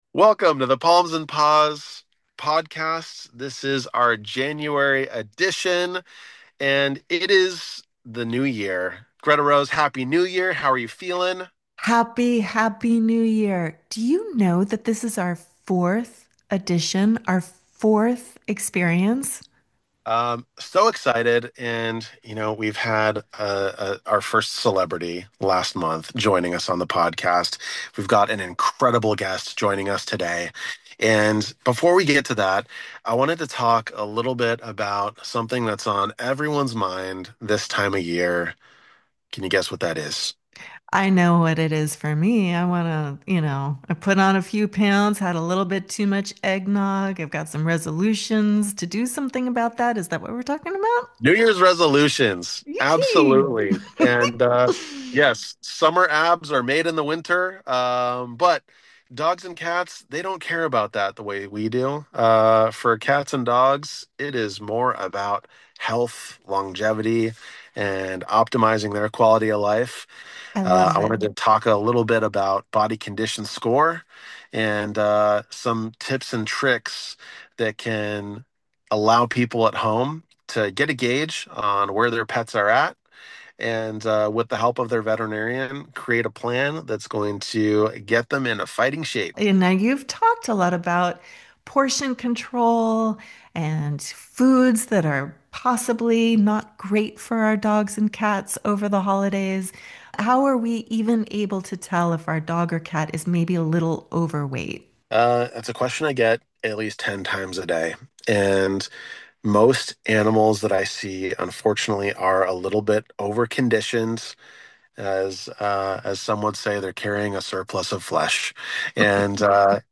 We hope this conversation stays with you long after the episode ends.